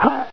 /hl2/sound/npc/combine_soldier/test/near/
pain2.ogg